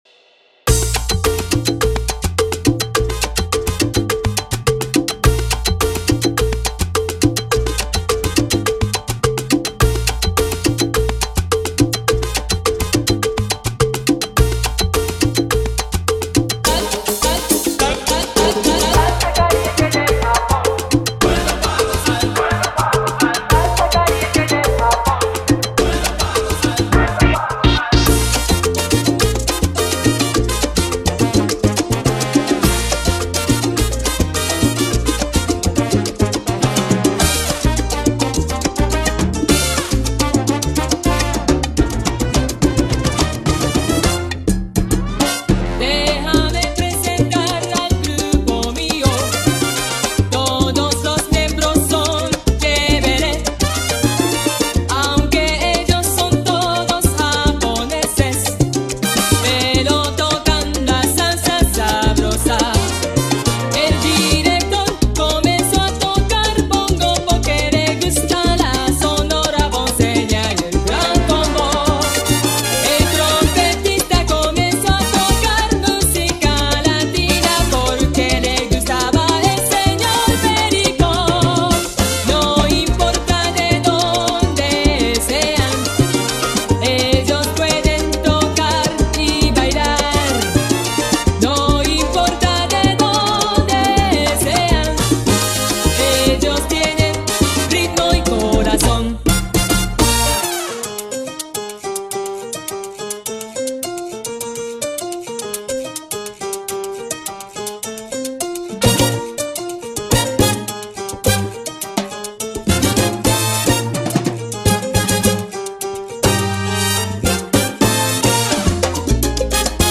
a thrilling mix by Miami’s dynamic DJ & VJ